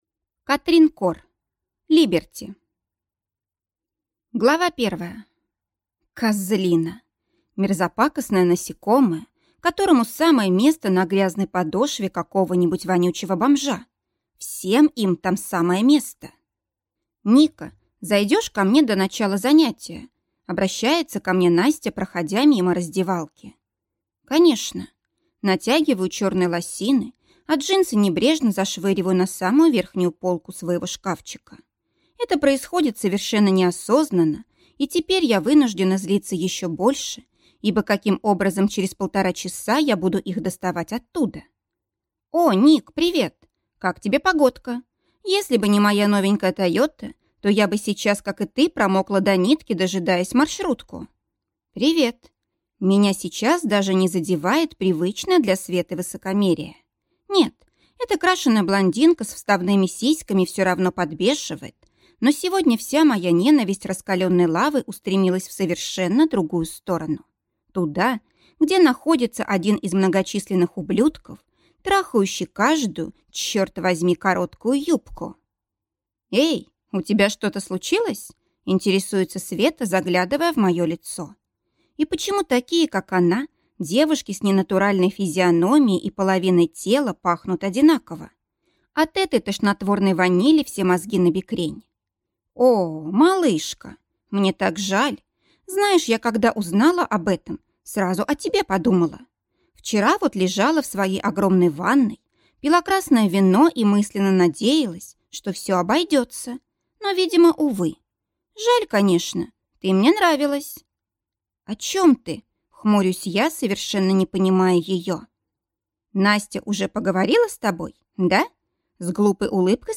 Аудиокнига Либерти | Библиотека аудиокниг